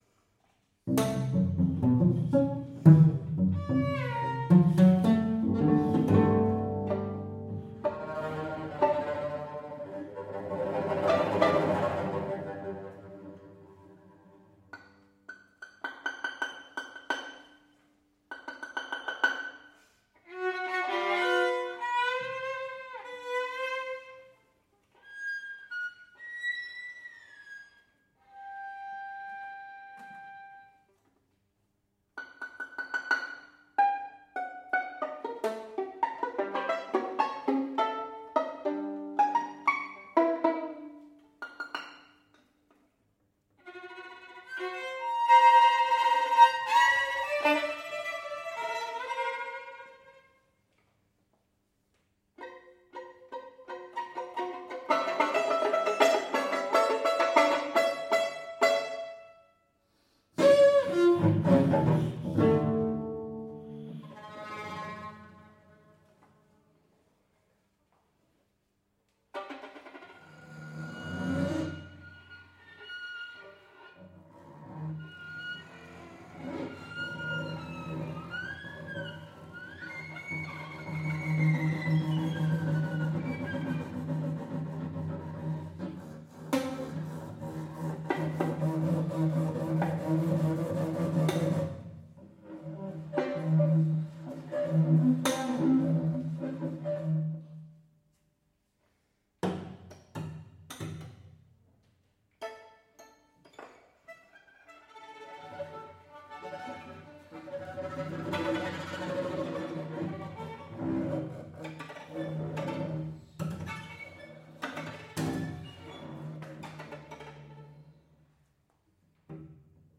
Chamber Music (2-9 musicians)
Eight Strings (2025) for Violin and Violoncello